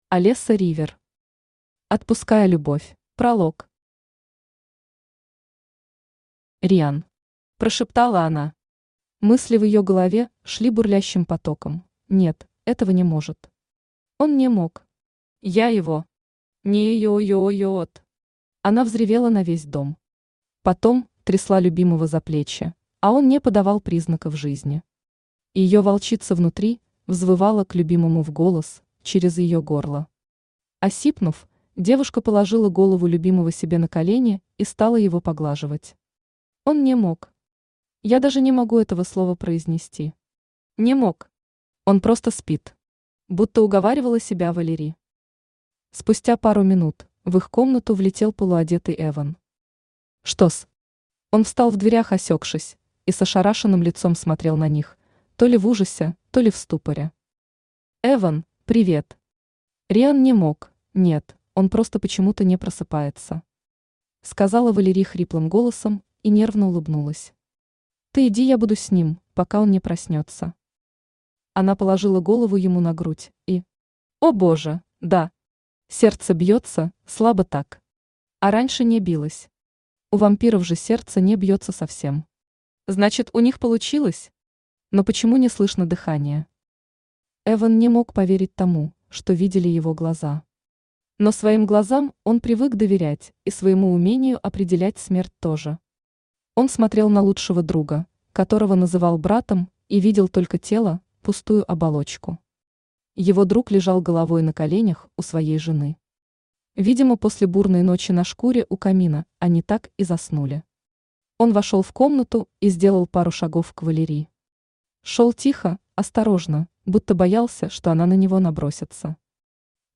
Аудиокнига Отпуская любовь | Библиотека аудиокниг
Aудиокнига Отпуская любовь Автор Алеса Ривер Читает аудиокнигу Авточтец ЛитРес.